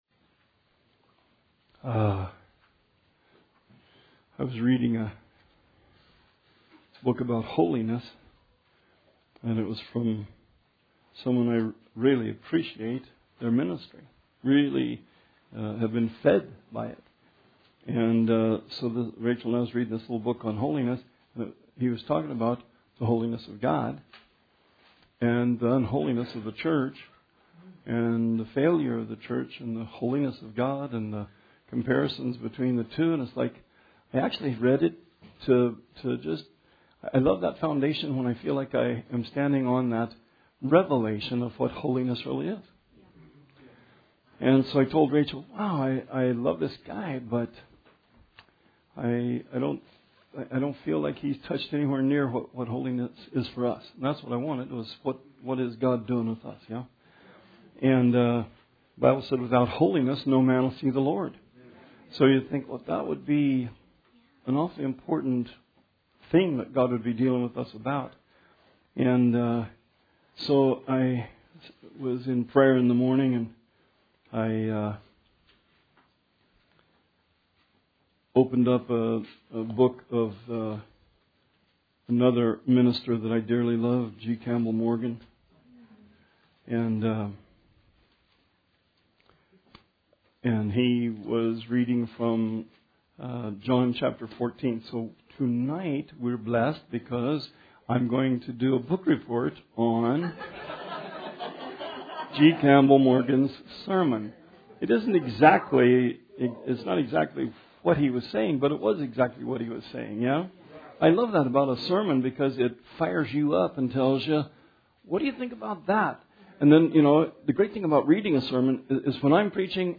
Bible Study 1/29/20